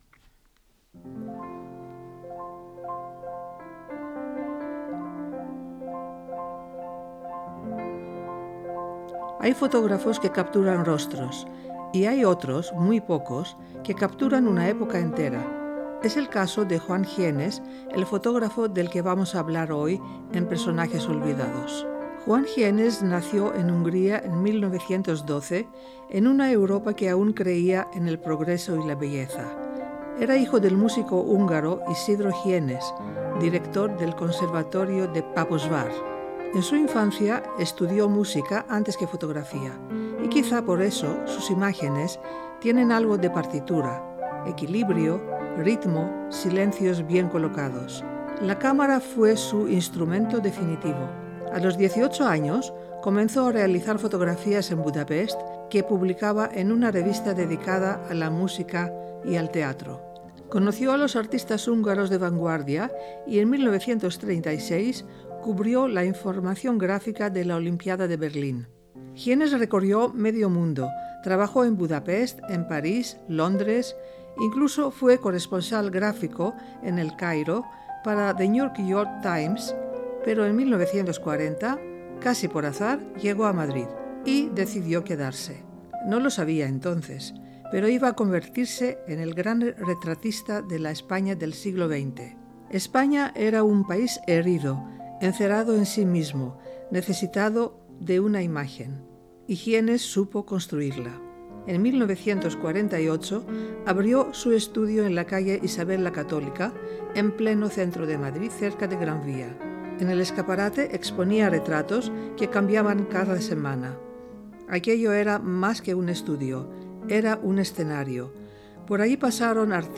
Juan-Gyenes-fotografo-montado-con-musica.mp3